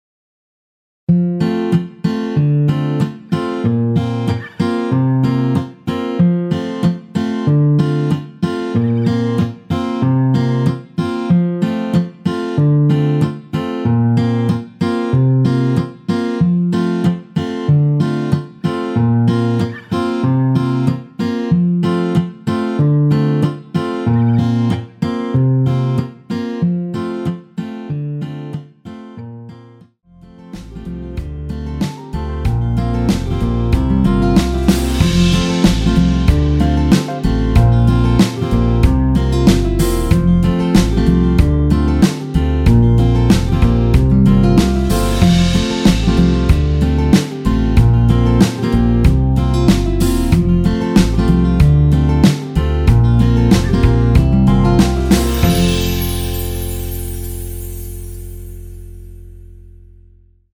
원키에서(+6)올린 MR입니다.
앞부분30초, 뒷부분30초씩 편집해서 올려 드리고 있습니다.